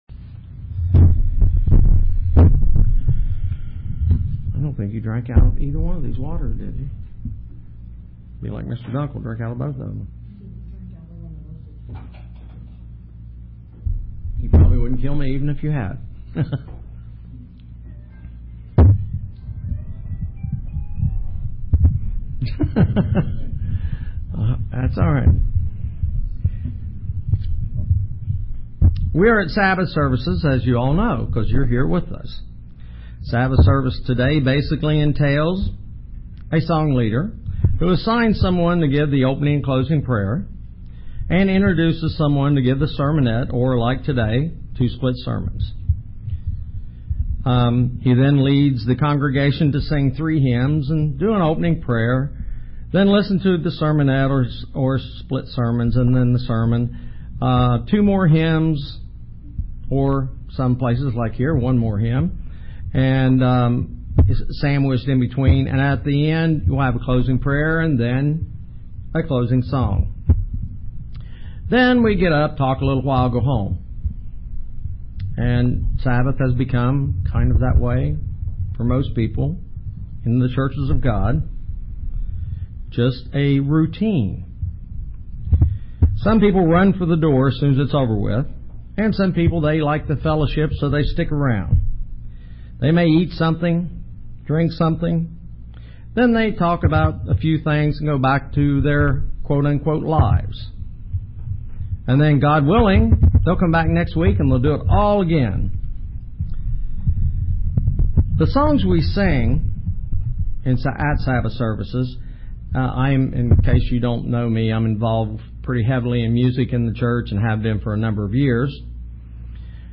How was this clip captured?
Given in Paintsville, KY